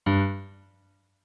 MIDI-Synthesizer/Project/Piano/23.ogg at 51c16a17ac42a0203ee77c8c68e83996ce3f6132